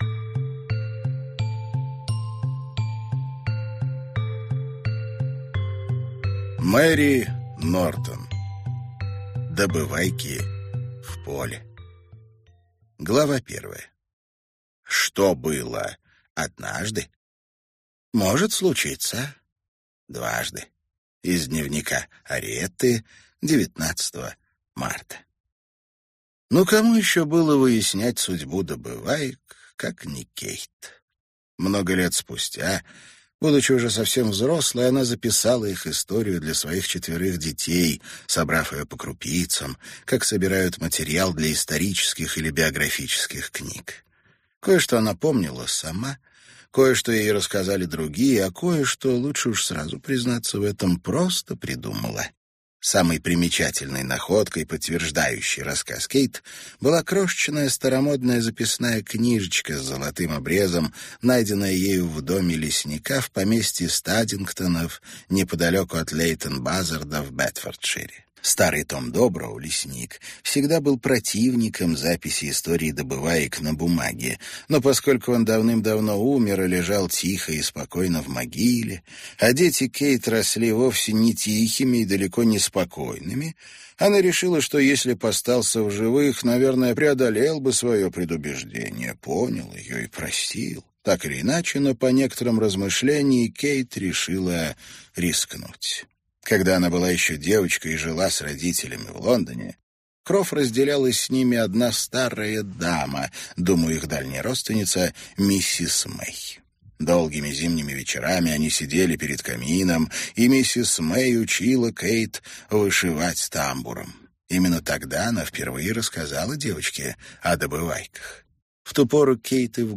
Аудиокнига Добывайки в поле | Библиотека аудиокниг